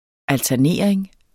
Udtale [ altæɐ̯ˈneˀɐ̯eŋ ]